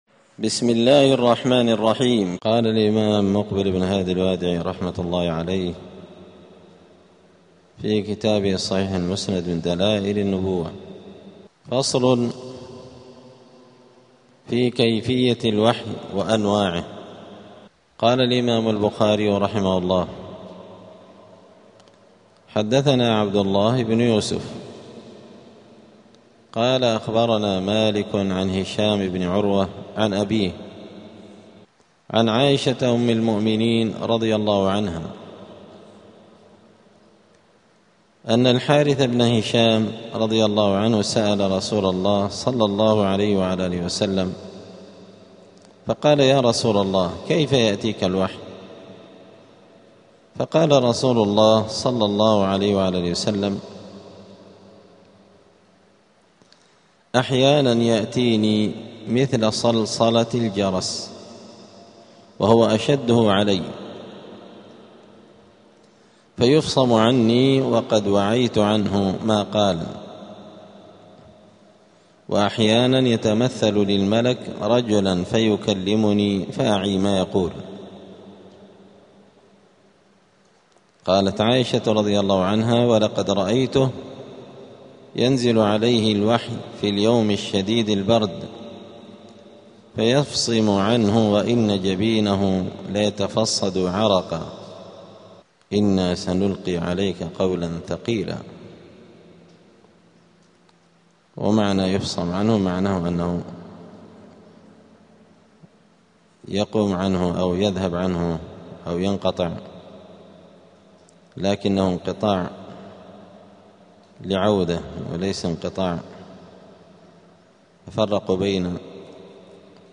*الدرس السادس (6) {فصل في كيفية الوحي وأنواعه}.*
دار الحديث السلفية بمسجد الفرقان قشن المهرة اليمن